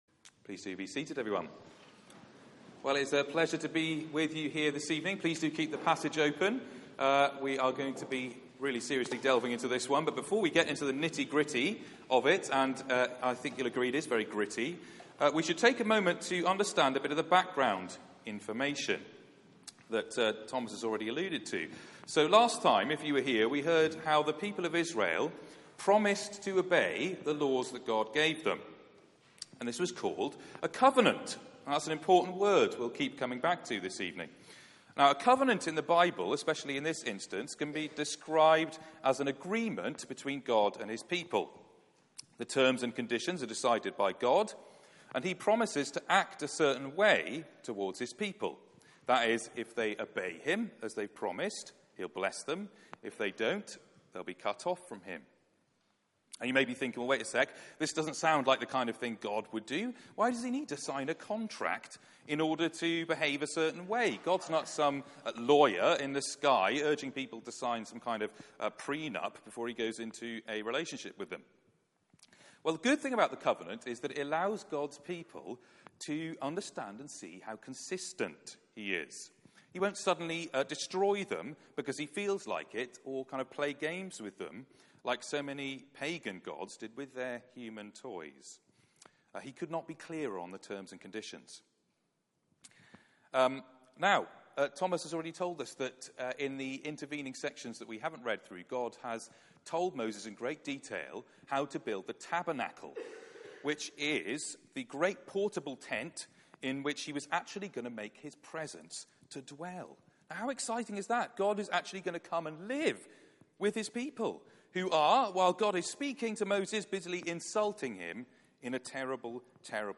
Passage: Exodus 32:1-33:6 Service Type: Weekly Service at 4pm